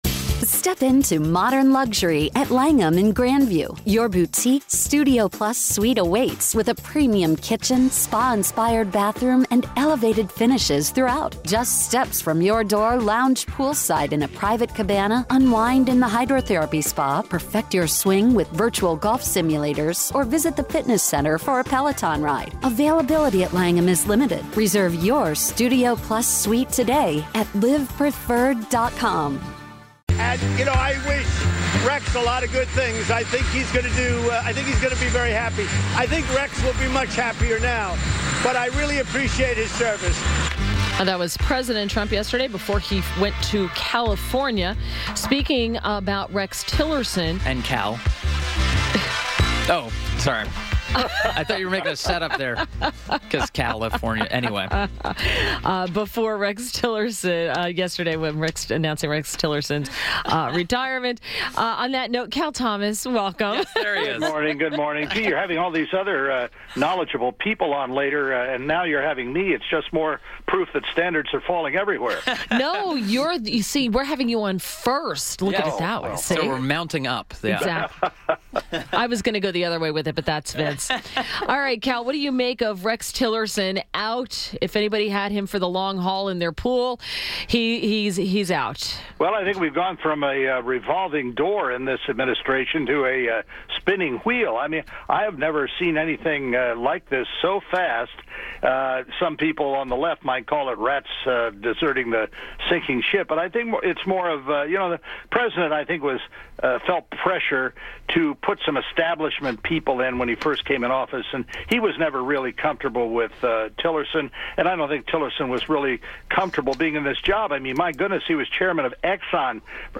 INTERVIEW - CAL THOMAS - syndicated columnist – discussed all the personnel changes at the State Department, CIA, and the White House